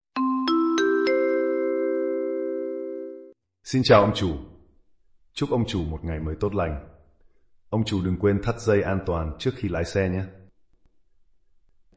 Âm thanh Chào khởi động Xe Ô tô Tiếng Việt
Thể loại: Tiếng chuông, còi
– Giọng nói thân thiện, vui tươi, phù hợp để cài làm âm thanh chào khi khởi động xe, tạo cảm giác chuyên nghiệp và gần gũi.
am-thanh-chao-khoi-dong-xe-o-to-tieng-viet-www_tiengdong_com.mp3